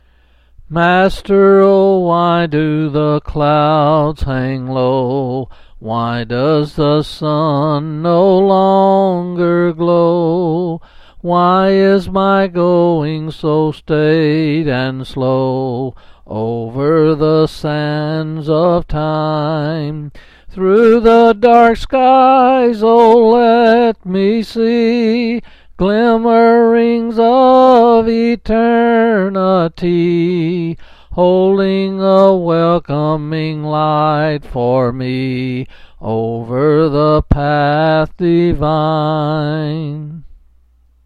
Quill Pin Selected Hymn